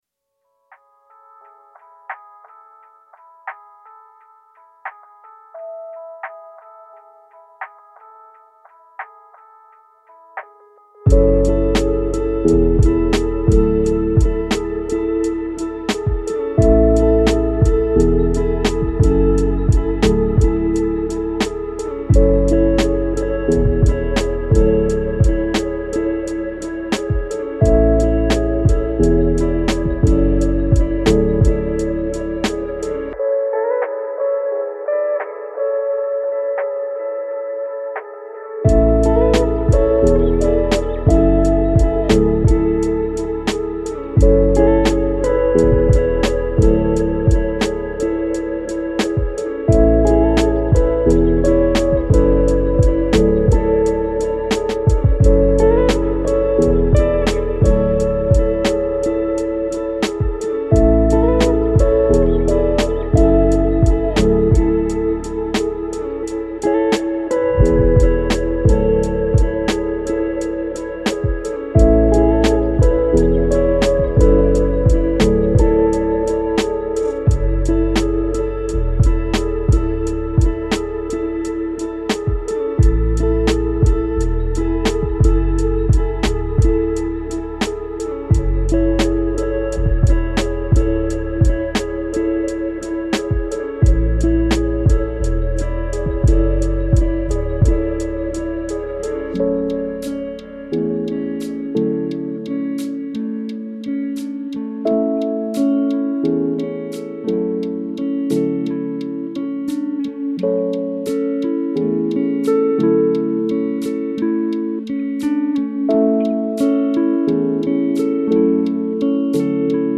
Мелодии для отдыха и вдохновения